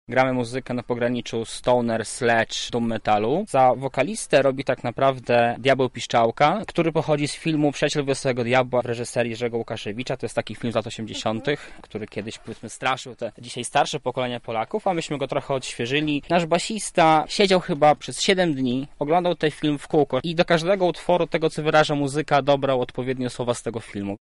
Ich muzyka jest ciężka i powolna, a w tekstach roi się od złych duchów.
W skład grupy wchodzą jedynie instrumentaliści.